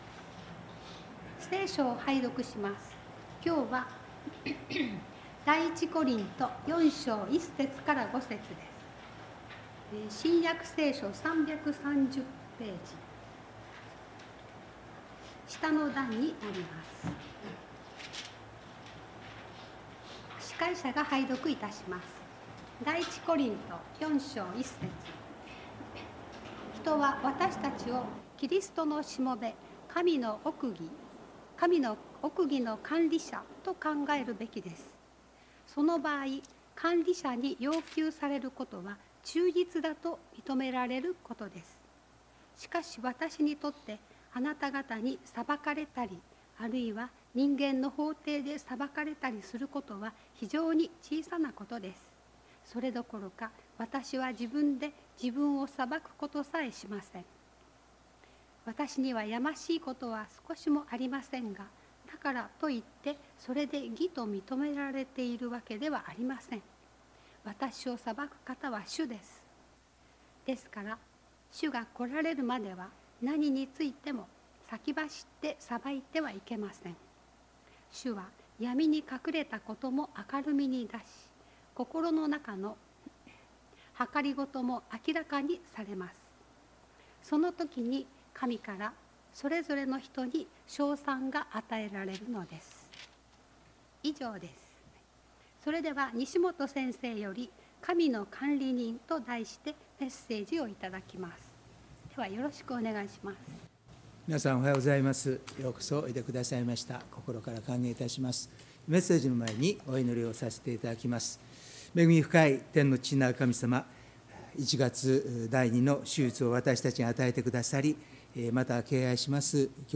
礼拝メッセージ「神の管理人」│日本イエス・キリスト教団 柏 原 教 会